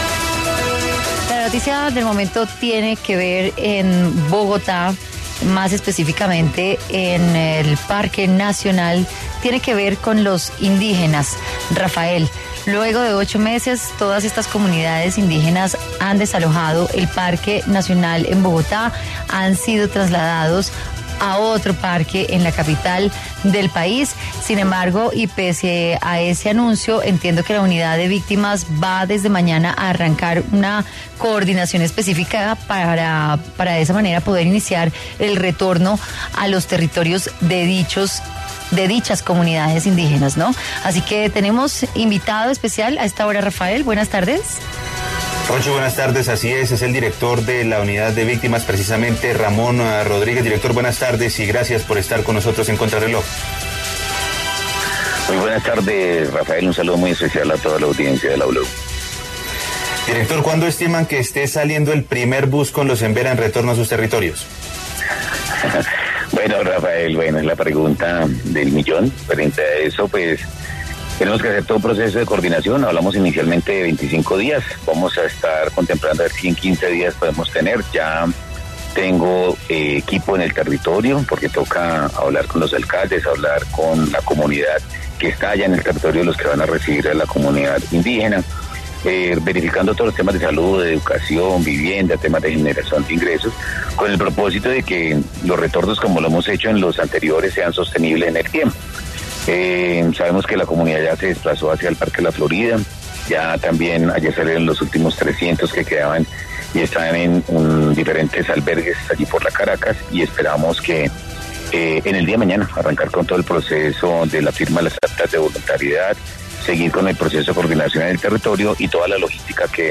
En entrevista con Contrarreloj, el director de la Unidad de Víctimas, Ramón Rodríguez, se refirió al retorno de los indígenas Embera a sus territorios, quienes abandonaron completamente el Parque Nacional y se encuentran en distintos resguardos de la capital del país a la espera de coordinar su retorno a sus territorios.